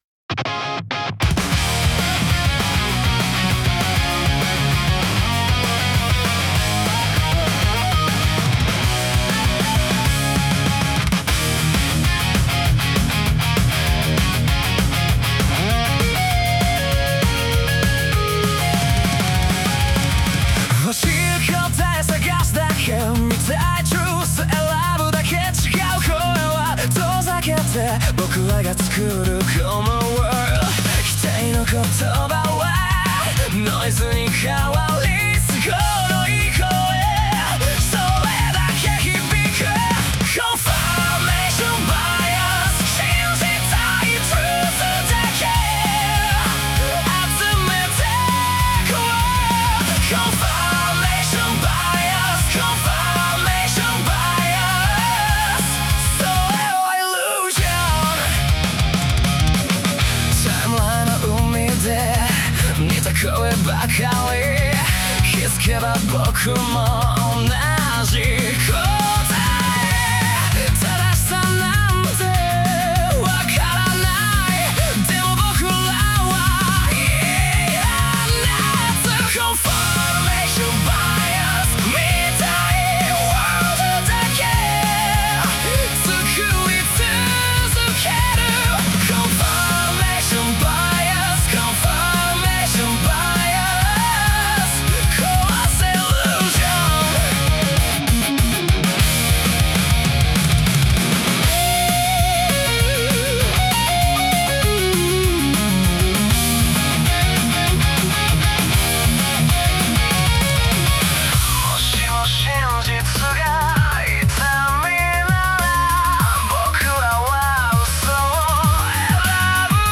男性ボーカル
イメージ：オルタナティブ,邦ロック,J-ROCK,男性ボーカル